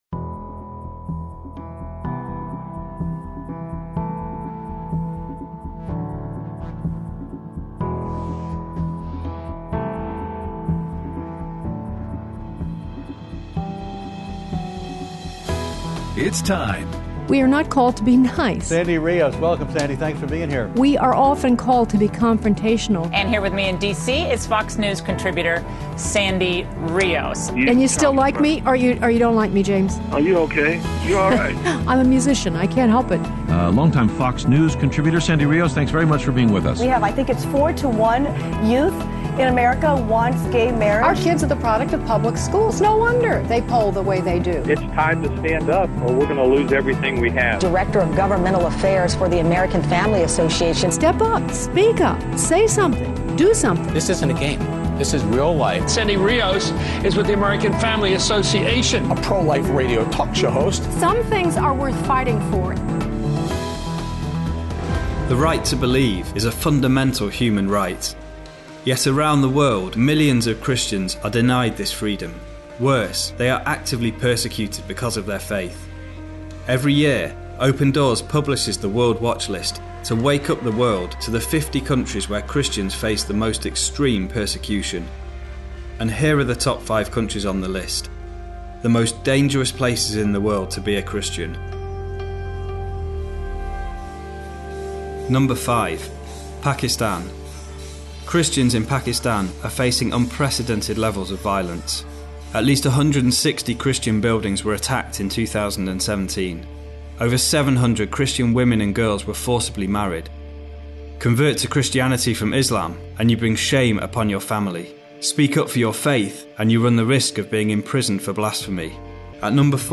Aired Friday 1/26/18 on AFR 7:05AM - 8:00AM CST